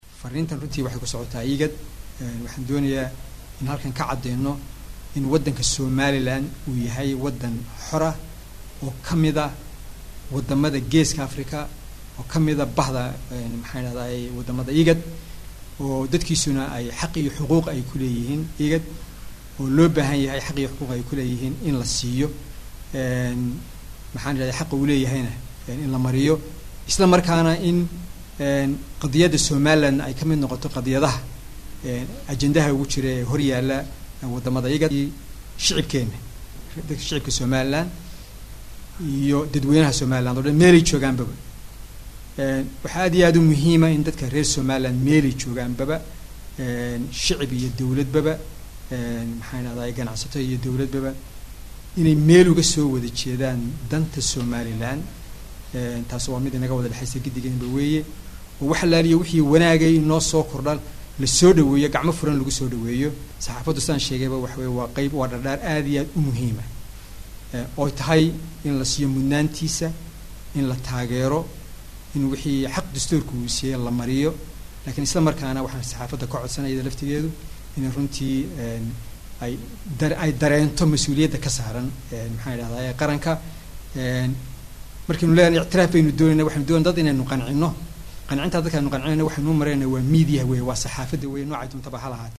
Wasiirka arimaha dibada maamulka Somaliland Dr Sacad Cali Shire oo saxaafada kula hadlay magaalada Hargeysa ayaa wuxuu sheegay in Somaliland ay tahay dal ka madax banaan Soomaaliya inteeda kale ayna doonayaan xaqa ay helaan wadamada IGAD ka tirsan.